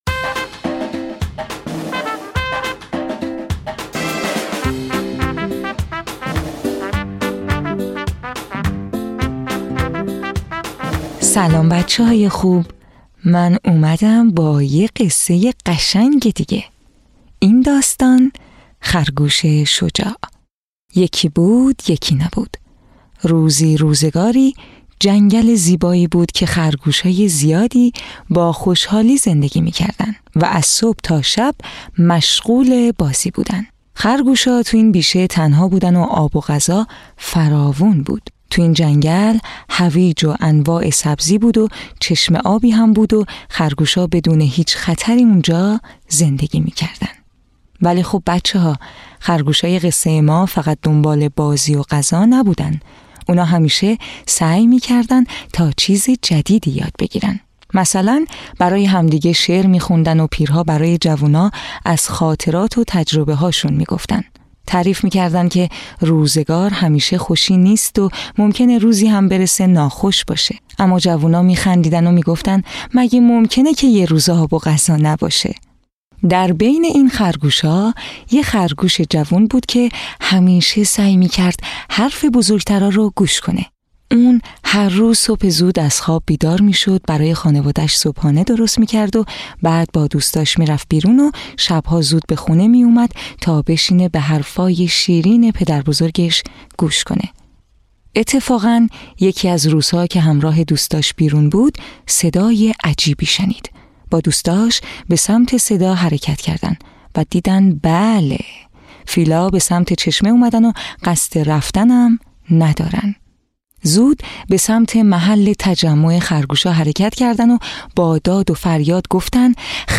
قصه های کودکانه صوتی- این داستان: خرگوش شجاع
تهیه شده در استودیو نت به نت